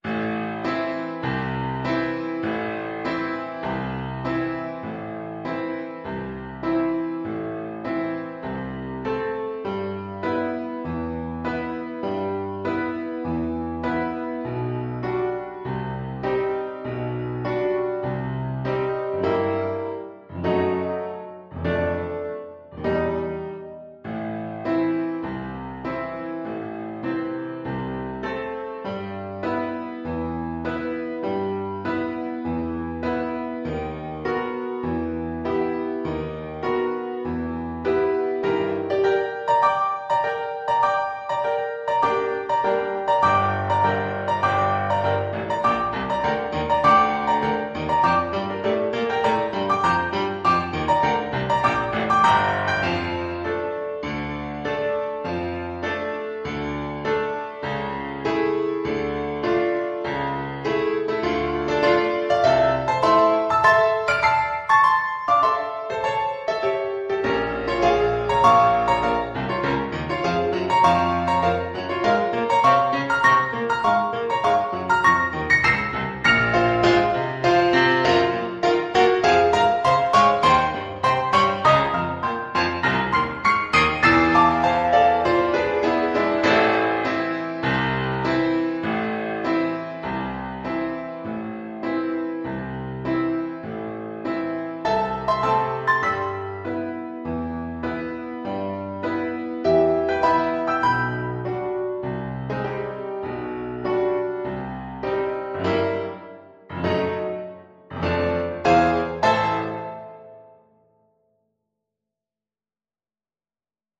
Classical (View more Classical Clarinet Music)